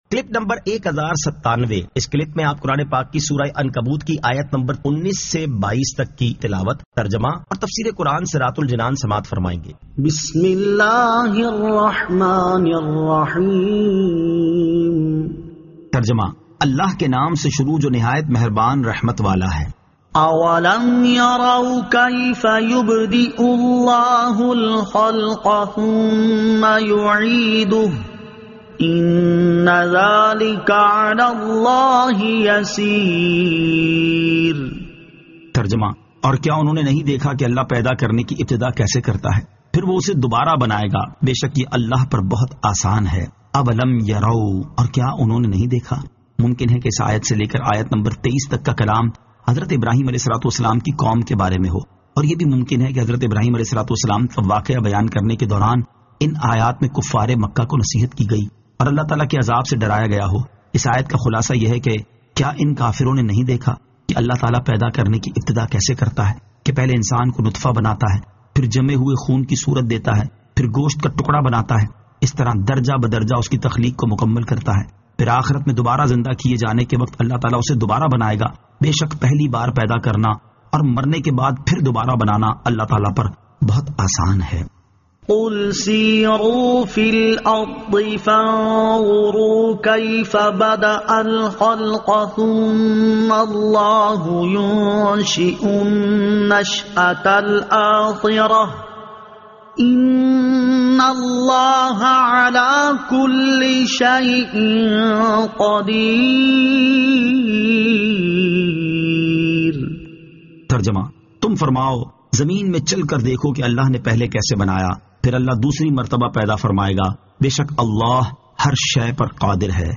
Surah Al-Ankabut 19 To 22 Tilawat , Tarjama , Tafseer